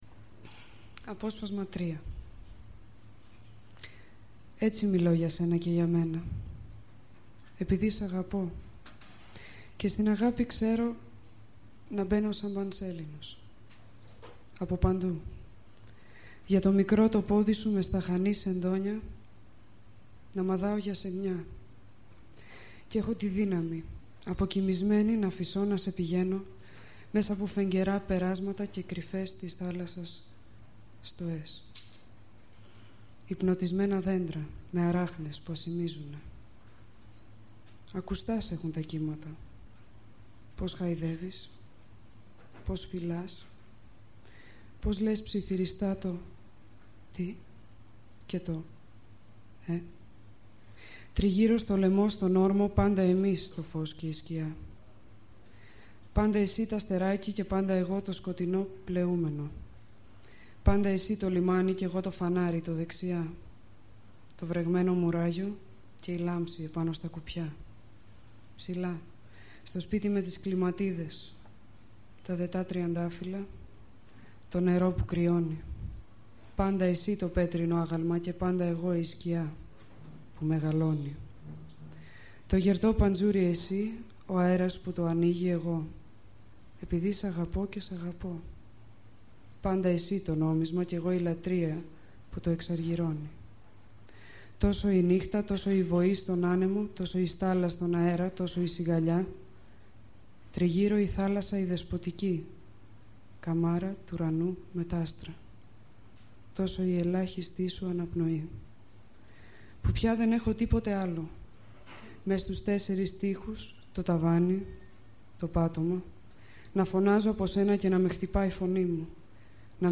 ������������ ���� ���������� - ������ O������� �������� - Elytis Reading